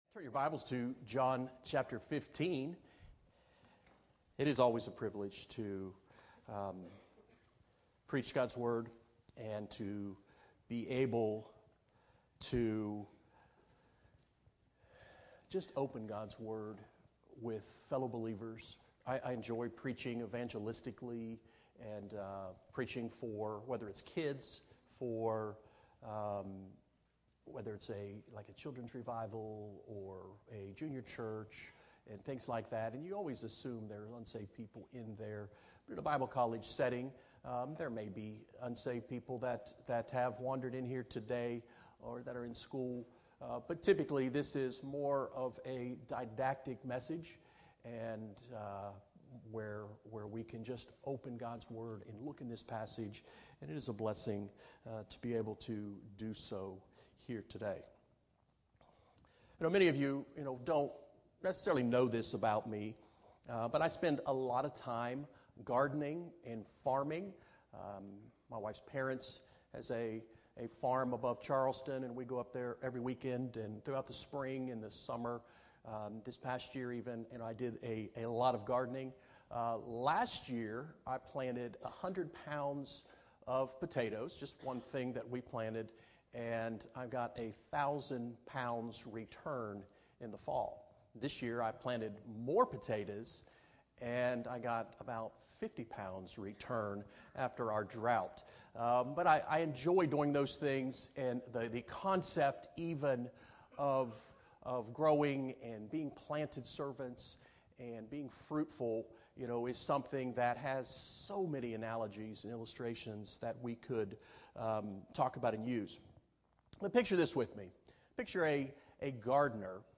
Bible sermon audio is available for download, as recorded at Appalachian Bible College chapel services.